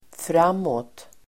Uttal: [fr'am:åt]